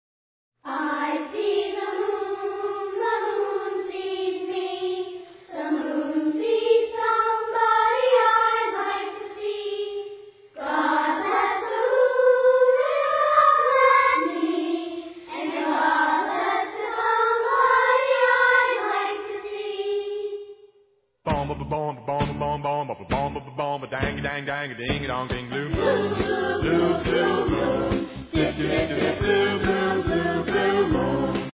excellent rock 'n' roll with wacky skits